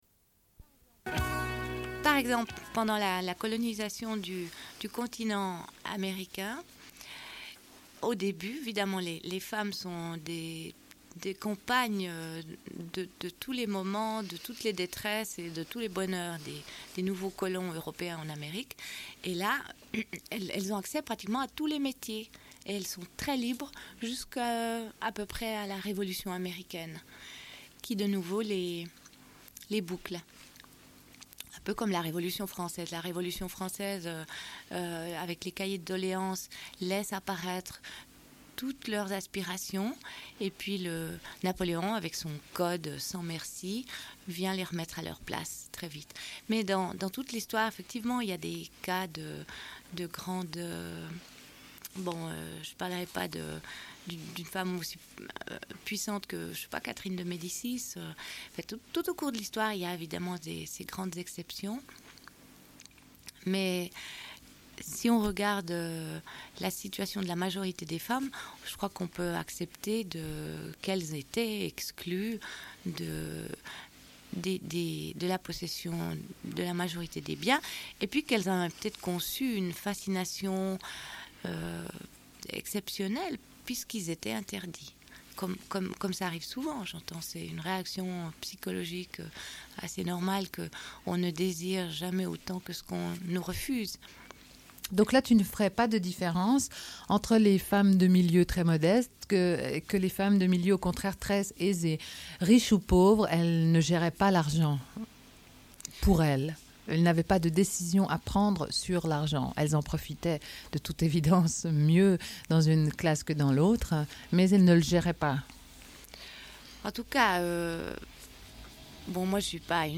Une cassette audio, face A31:21